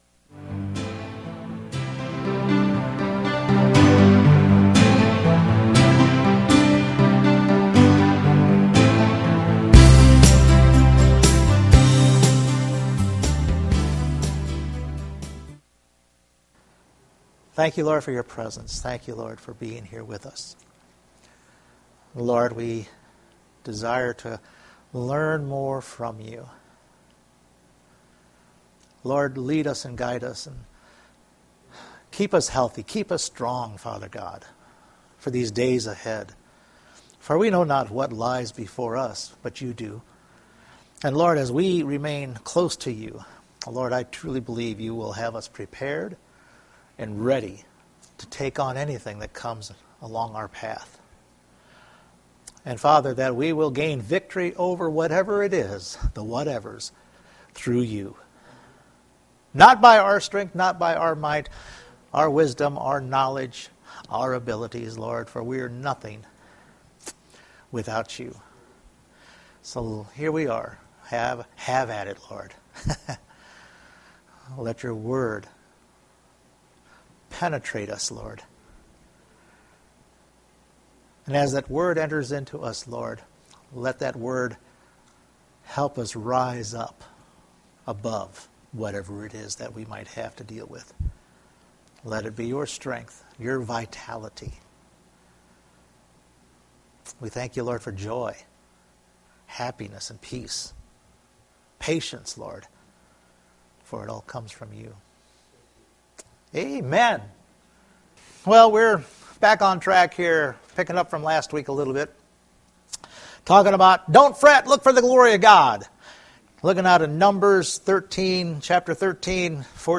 Number 14:10 Service Type: Sunday Morning God will show you the land He has for you…It’s up to you to posses it.